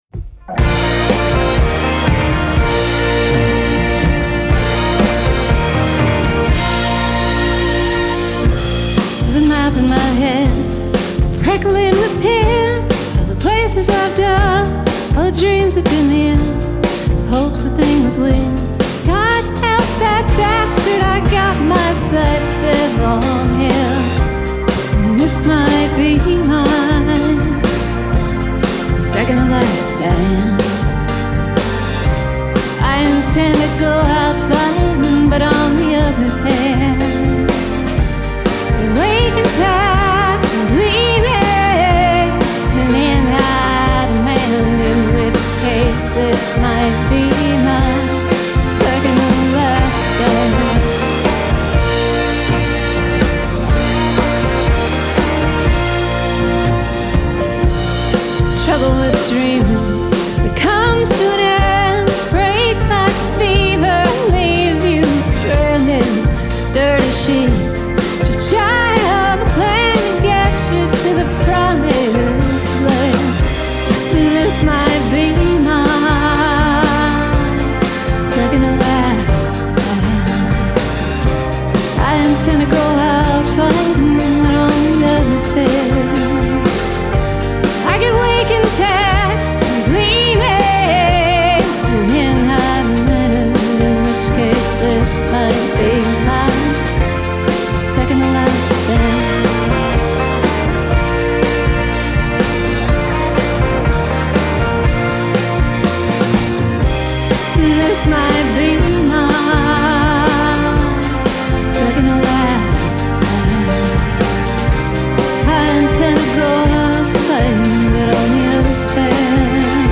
Genre: Americana